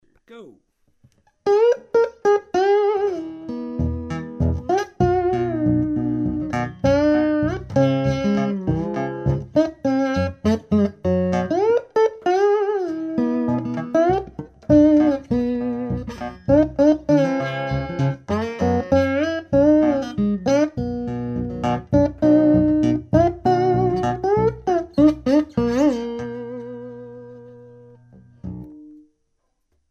Dobro